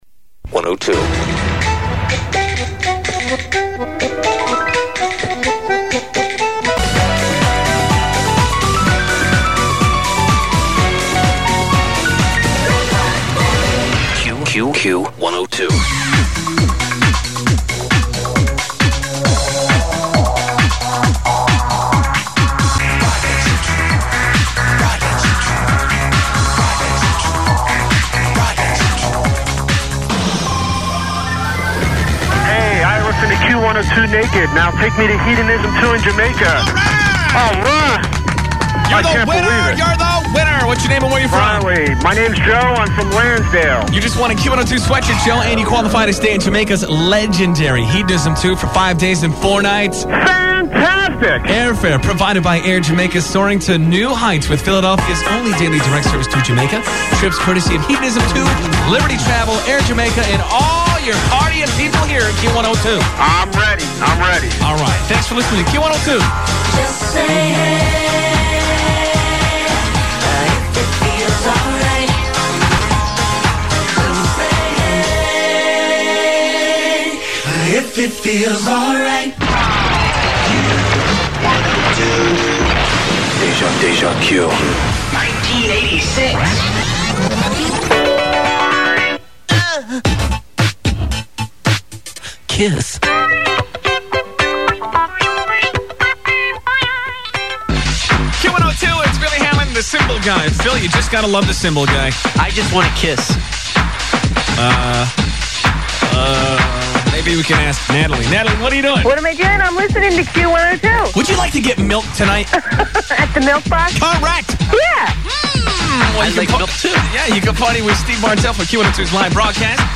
It just seems like they were trying too hard to make the station sound “hip”, for lack of a better term.